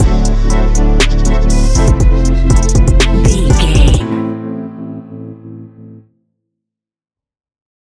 Ionian/Major
A♭
chilled
laid back
Lounge
sparse
new age
chilled electronica
ambient
atmospheric